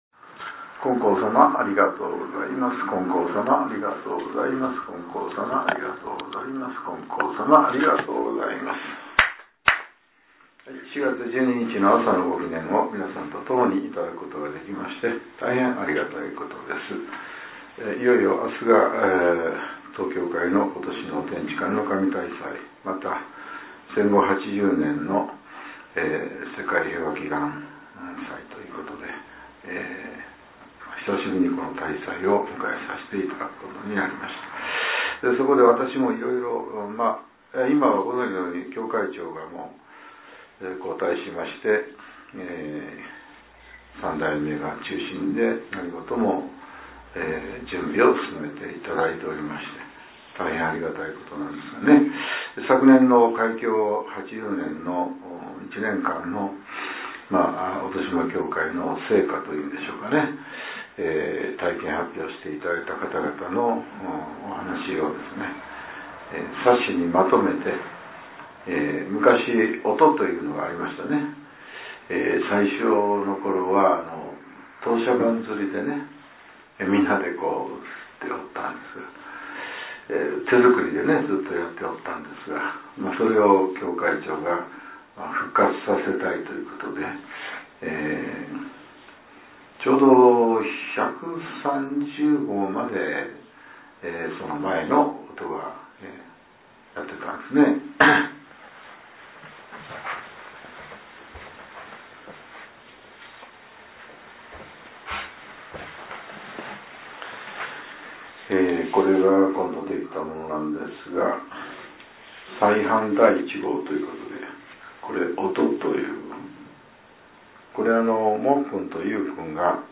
令和７年４月１２日（朝）のお話が、音声ブログとして更新されています。